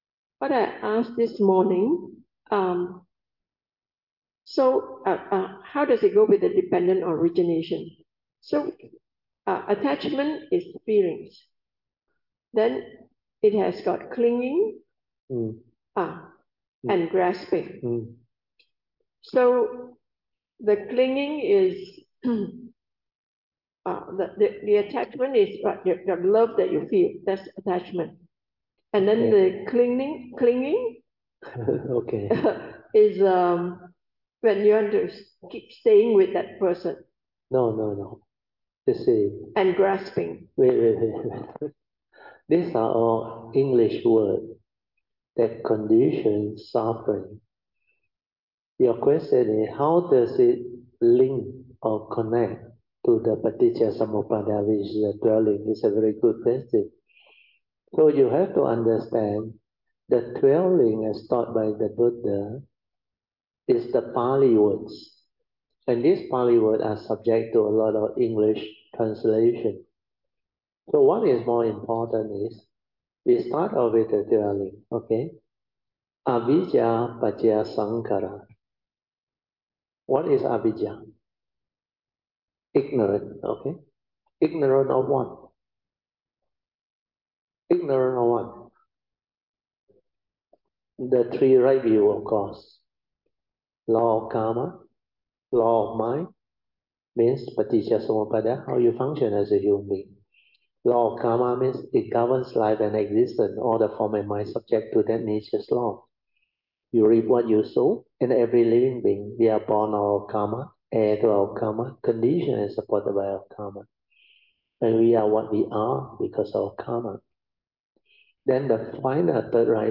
Cameron and Sumeru Klang Retreats